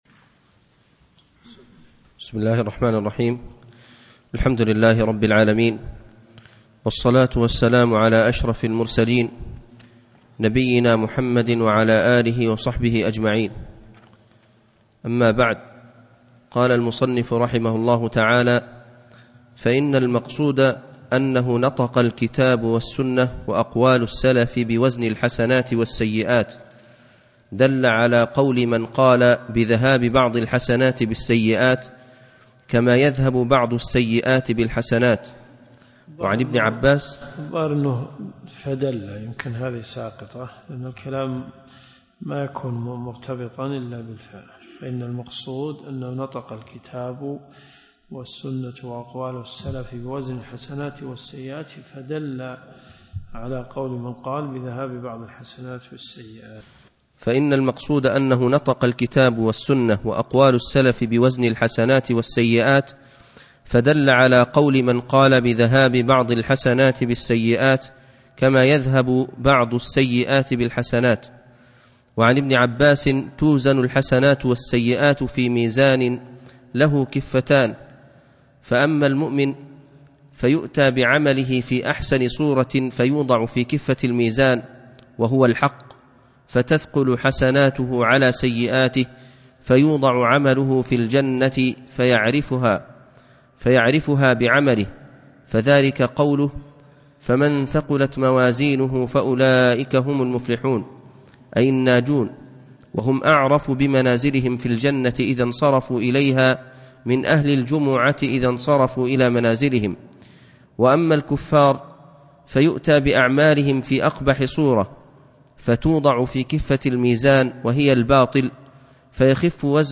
الرئيسية الدورات الشرعية [ قسم السلوك ] - تزكية النفس لابن تيمية . 1428 .